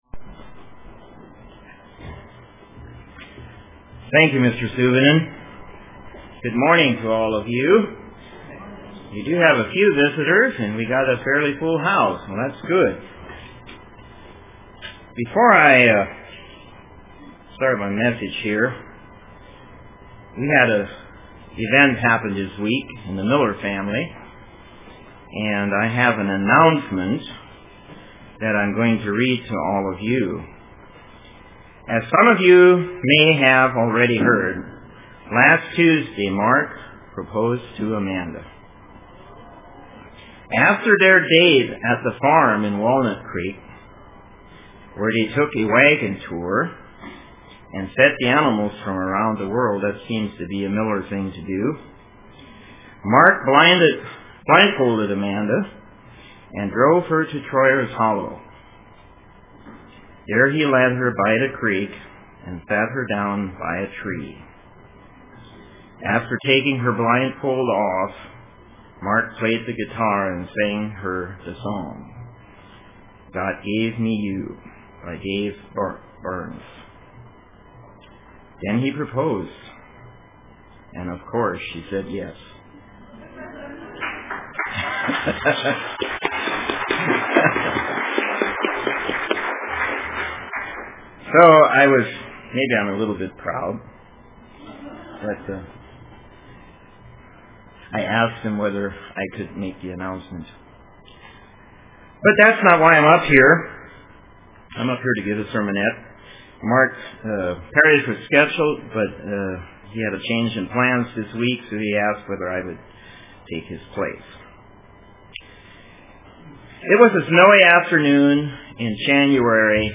Print The Greatest Hero UCG Sermon